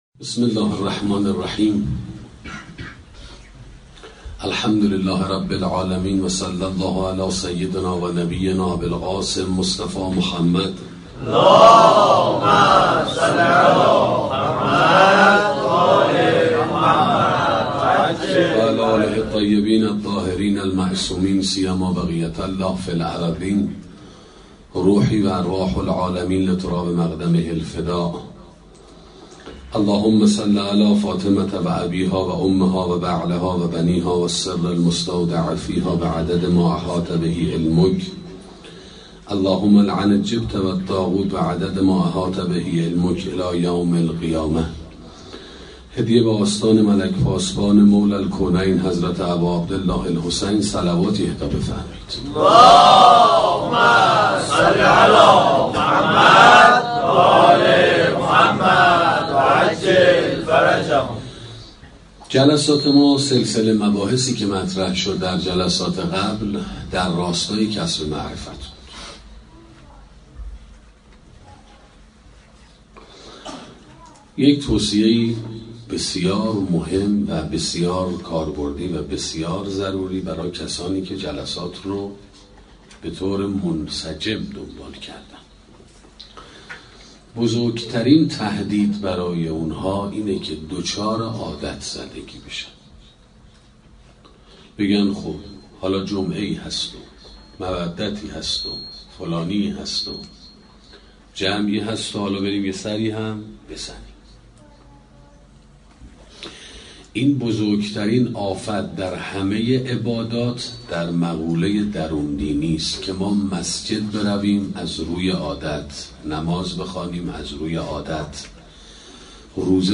سخنرانی شناخت اقدامات حضرت زهرا (س) 1